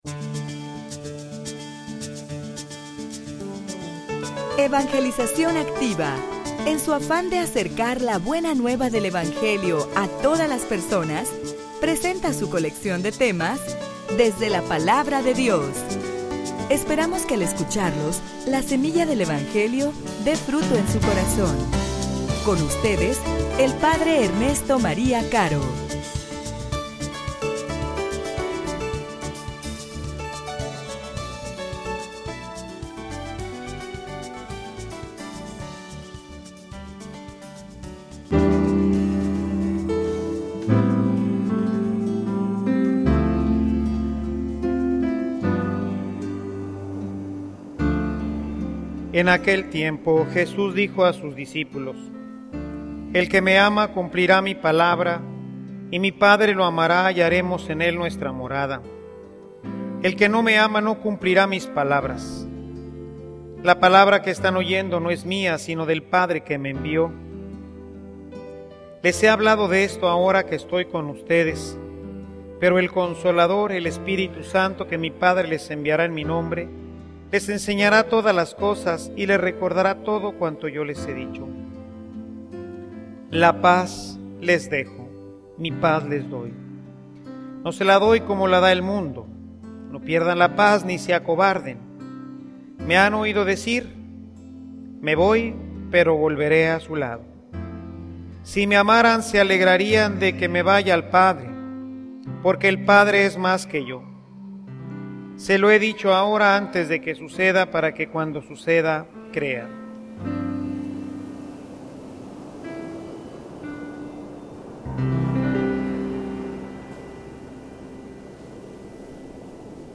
homilia_Diezmo_Un_ejercicio_de_fe_y_de_amor.mp3